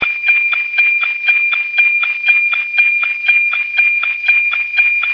RealAudio FAX/SSTV Sounds
WEFAX NOAA 14